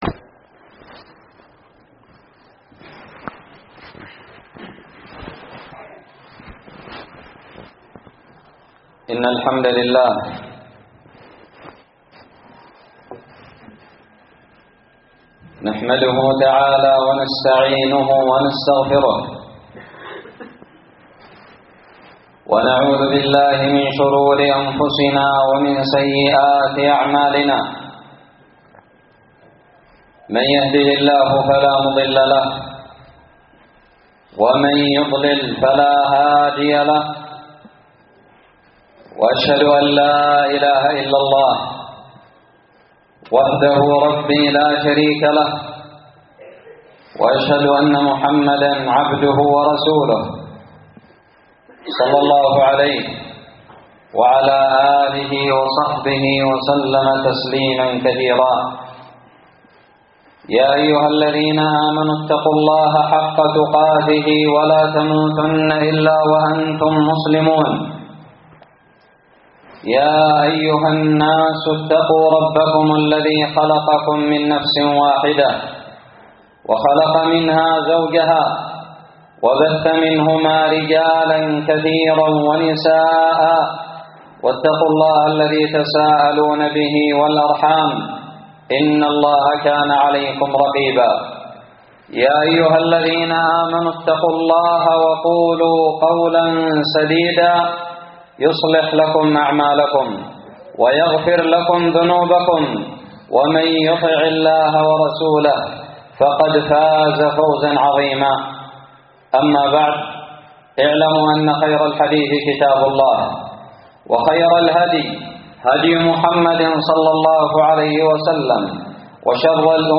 خطب الجمعة
ألقيت بدار الحديث السلفية للعلوم الشرعية بالضالع في 6 ربيع أول 1439هــ